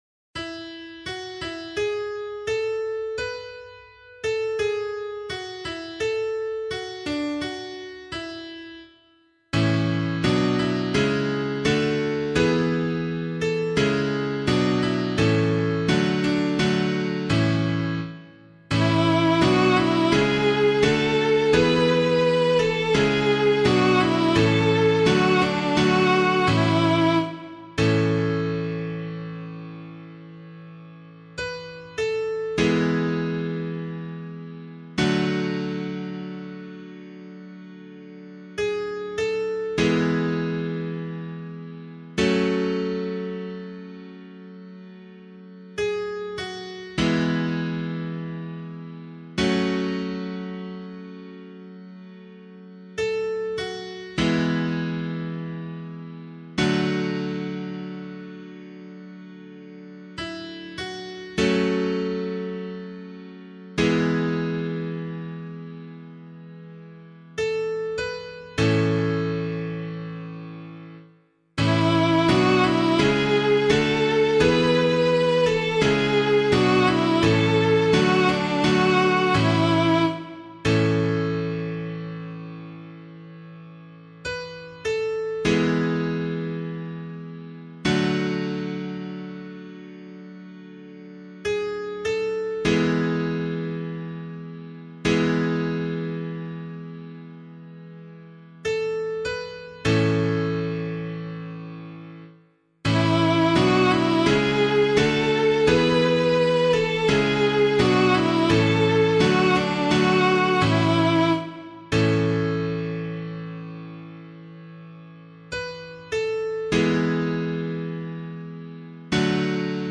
022 Easter Vigil Psalm 5 [LiturgyShare 8 - Oz] - piano.mp3